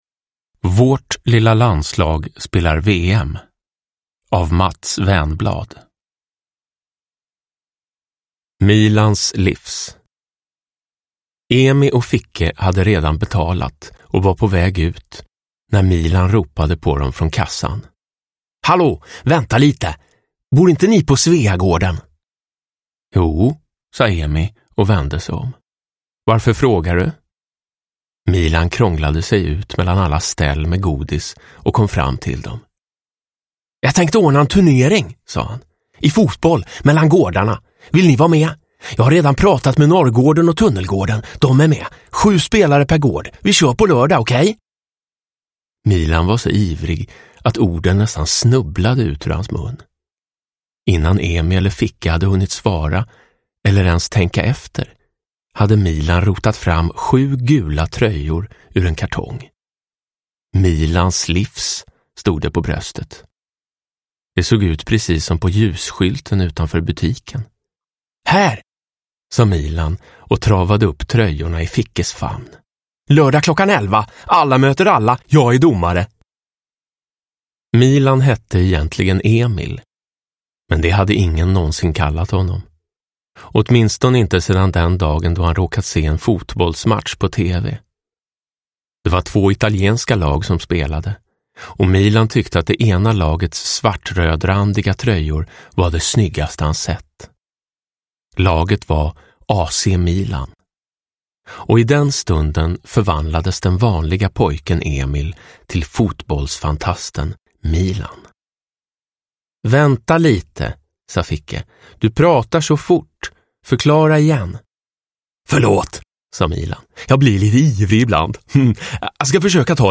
Uppläsare: Jonas Karlsson